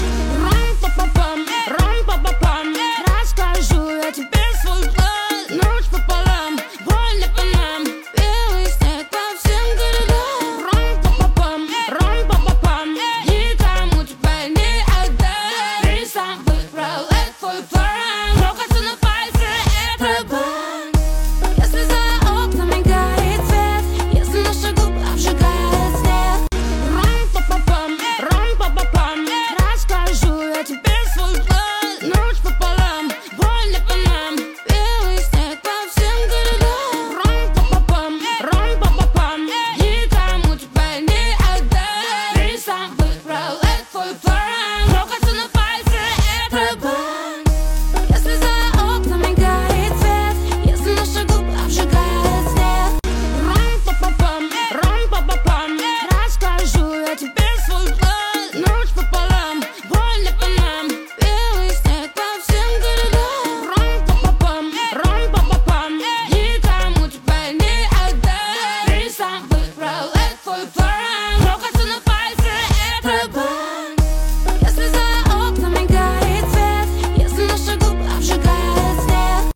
Жанр: Русские песни